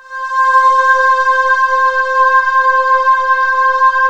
Index of /90_sSampleCDs/USB Soundscan vol.28 - Choir Acoustic & Synth [AKAI] 1CD/Partition D/09-VOCODING
VOCODINGC5-L.wav